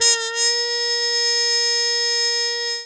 Index of /90_sSampleCDs/E-MU Formula 4000 Series Vol. 1 - Hip Hop Nation/Default Folder/Trumpet MuteFX X